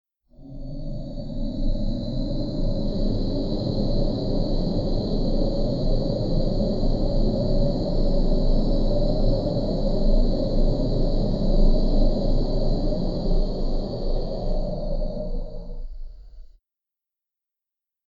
deathmaze_drone.mp3